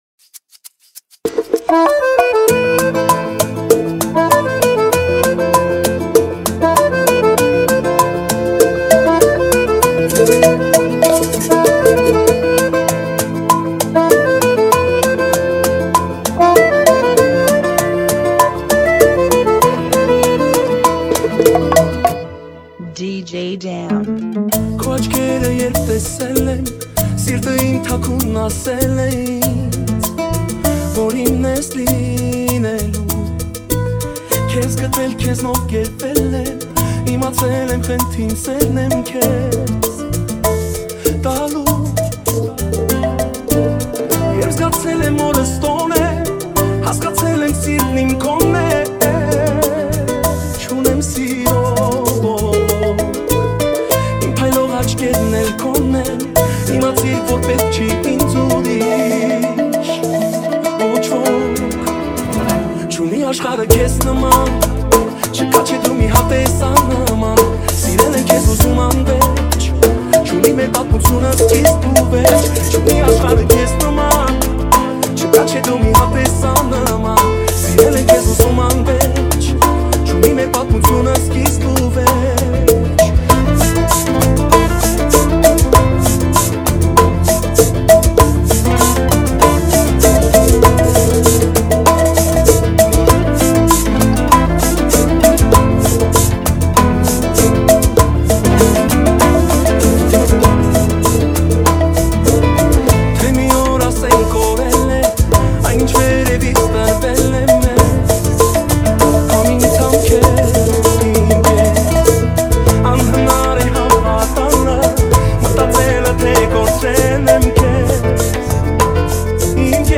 98 BPM
Genre: Bachata Remix